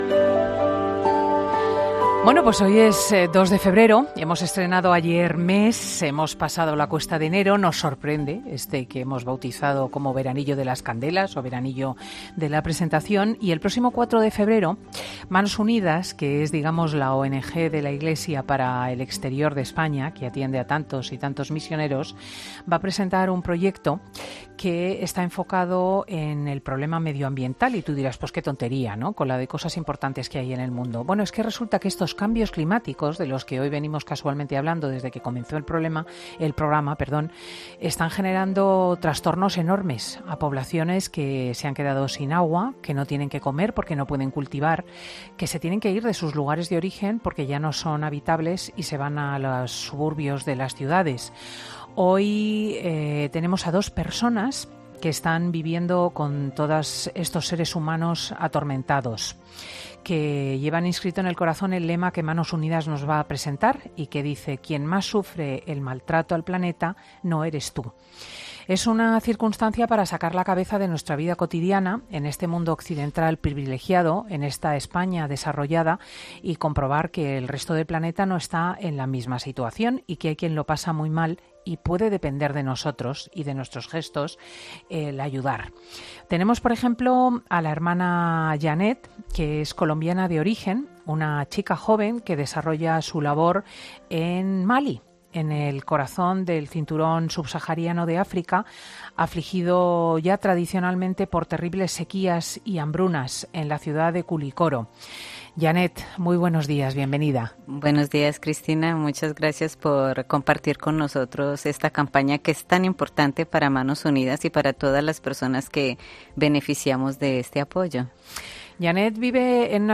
Dos miembros de Manos Unidas nos hablan, en Fin de Semana, de la campaña 'Quien más sufre el maltrato al planeta no eres tú'